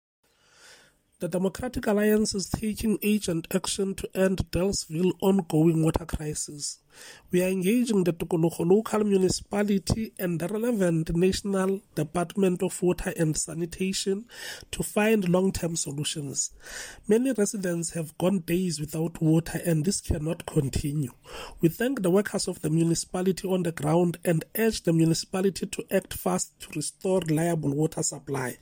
Sesotho soundbite by Cllr Hismajesty Maqhubu